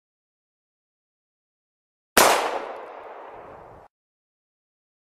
دانلود صدای تک تیر کلت در صحنه فیلم های درام از ساعد نیوز با لینک مستقیم و کیفیت بالا
جلوه های صوتی